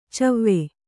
♪ cavve